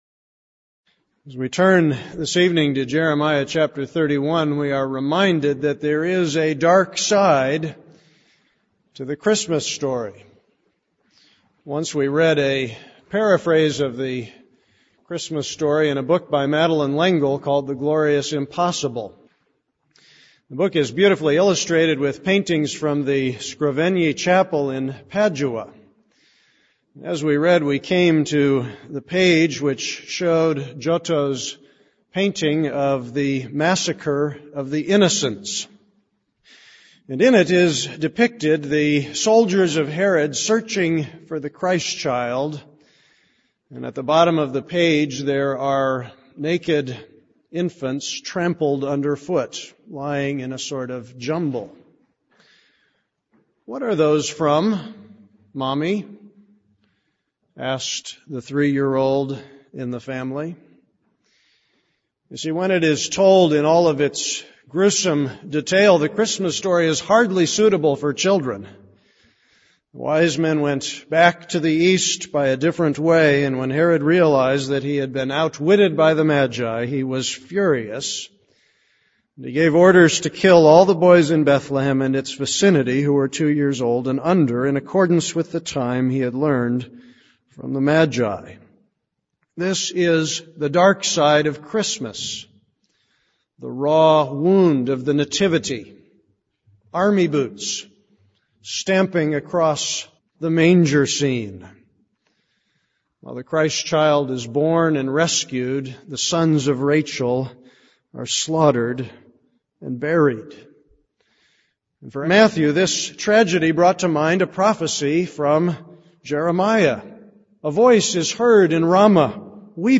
This is a sermon on Jeremiah 31:15.